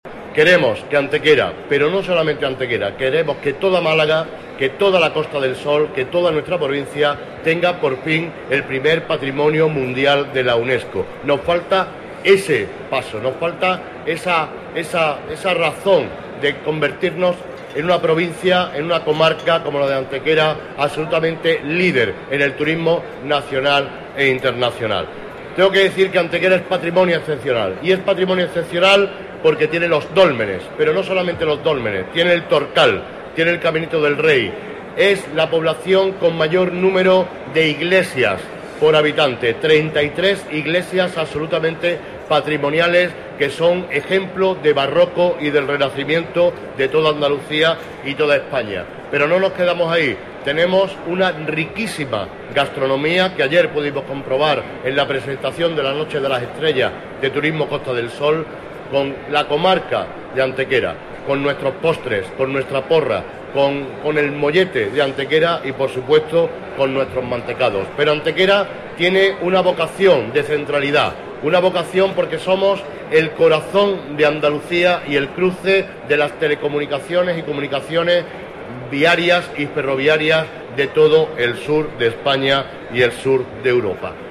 Bajo el lema “Patrimonio Excepcional”, el alcalde Manolo Barón ha presidido una rueda de prensa en el expositor de Turismo Costa del Sol en la que ha marcado, como objetivo común de toda la provincia, que Málaga consiga en julio del presente año su primer bien considerado como Patrimonio Mundial.
Cortes de voz